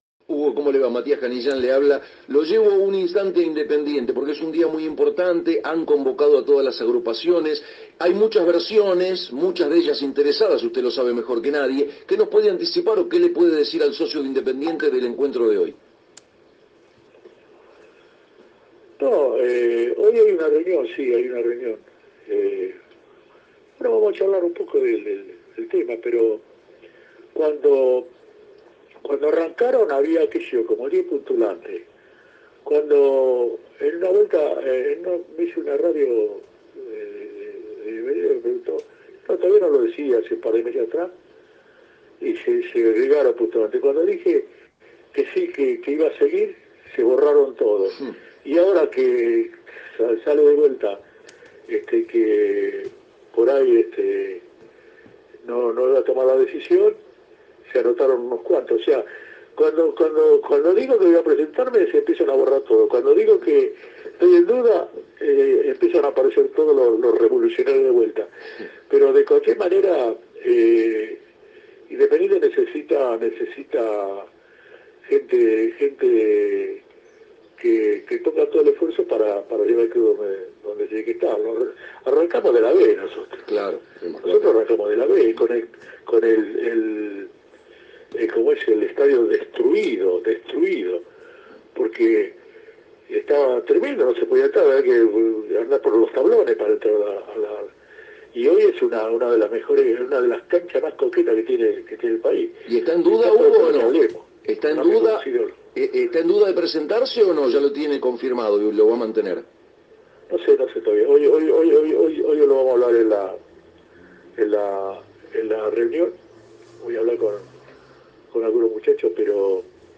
En el día de la cumbre política Hugo Moyano habló al respecto en AM 990